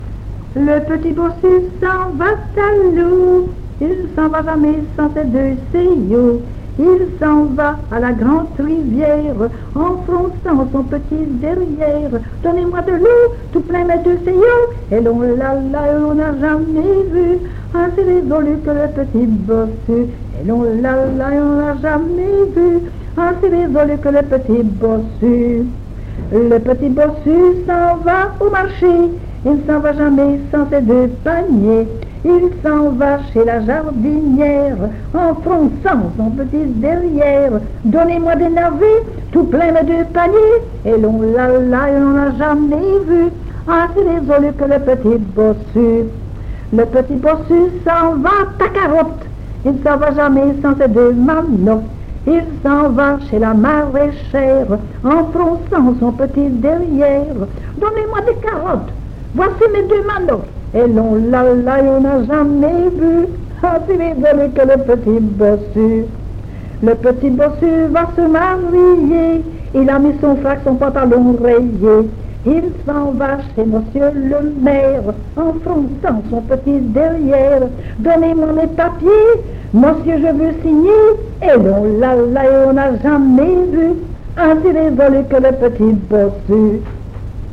Genre : chant
Type : ronde, chanson à danser
Province d'origine : Hainaut
Lieu d'enregistrement : Jolimont
Support : bande magnétique
Ronde.